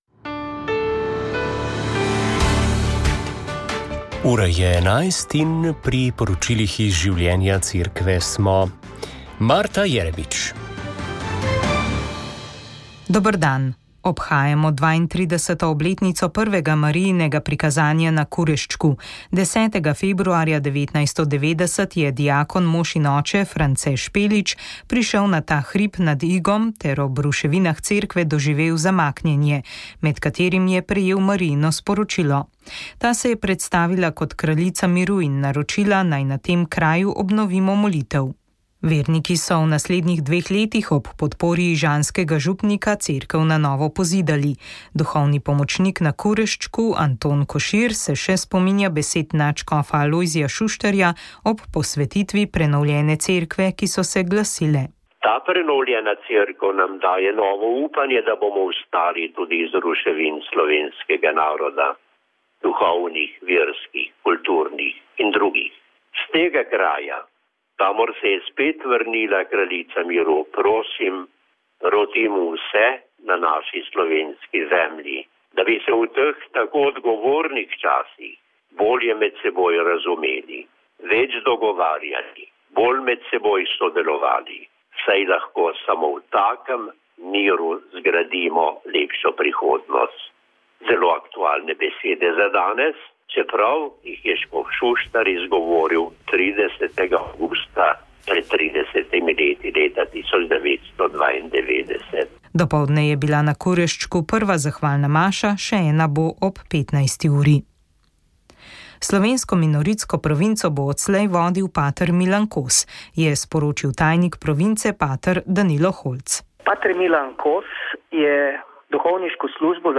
O knjigi in p. Corteseju so na predstavitvi v Ljubljani